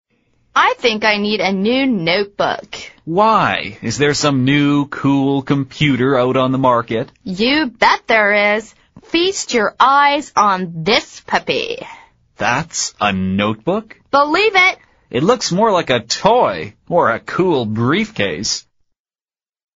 美语会话实录第94期(MP3+文本):Feast your eyes